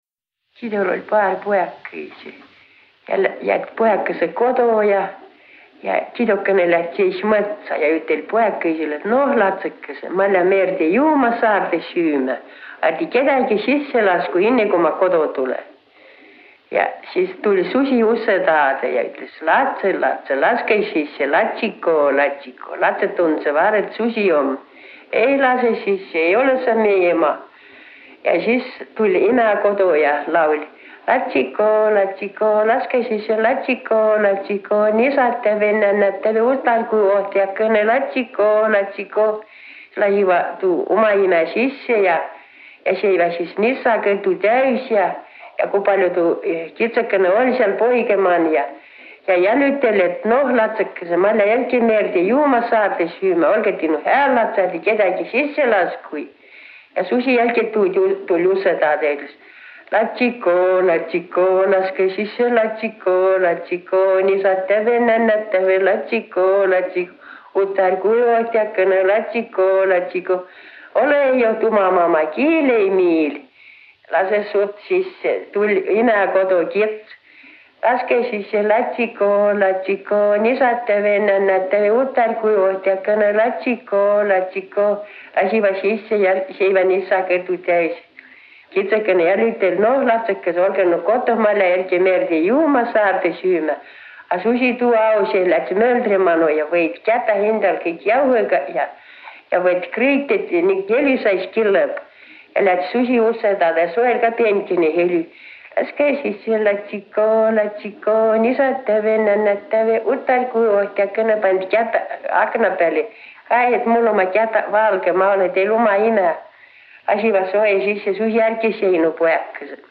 Muinasjutt “Kidol oll´ paar pujakõisi”